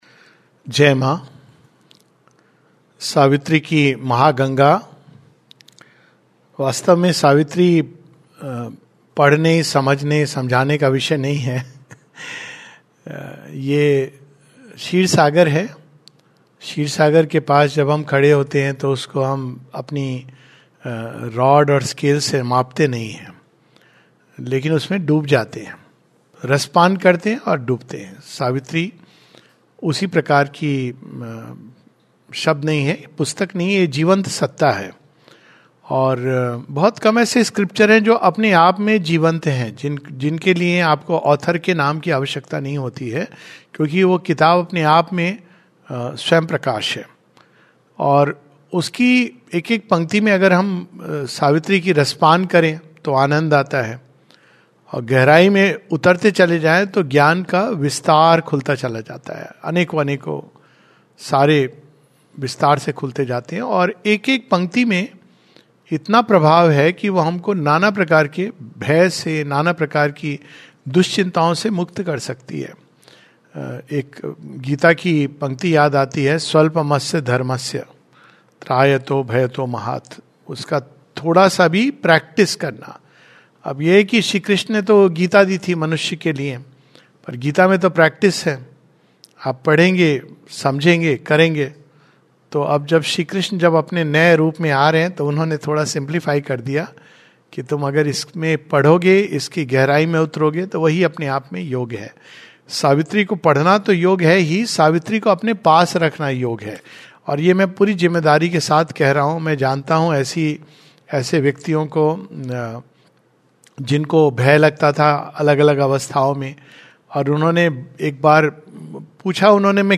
In this regular Webinar on Savitri in Hindi we take up a passage from Savitri, pages 66-67.